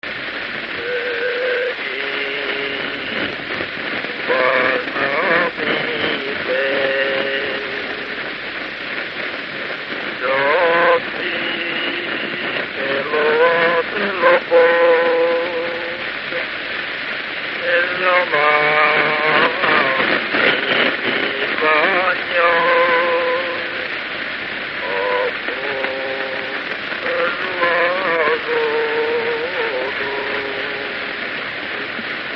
Moldva és Bukovina - Bukovina - Istensegíts
Stílus: 8. Újszerű kisambitusú dallamok
Szótagszám: 6.6.6.6
Kadencia: 1 (3) 3 1